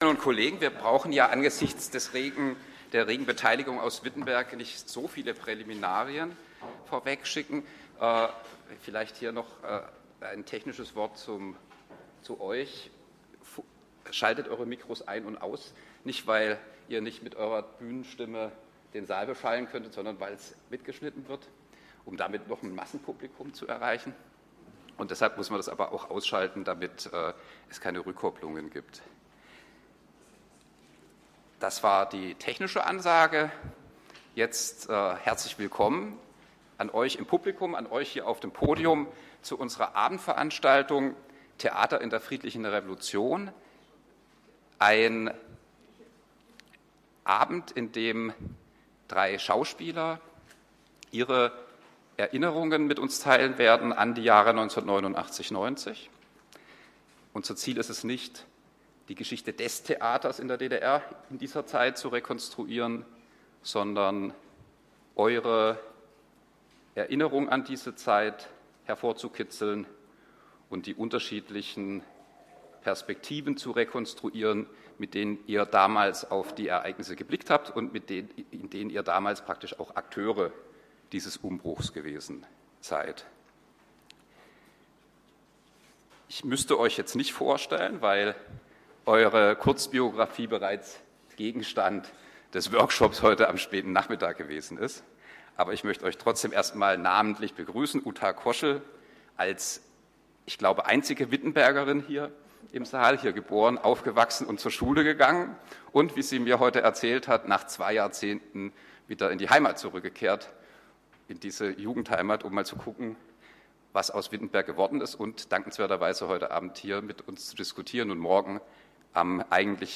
Die 9. Promovierendentage zur deutsch-deutschen Zeitgeschichte fanden vom 18. bis zum 21. Juli 2013 in Lutherstadt Wittenberg statt.
Podiumsdiskussion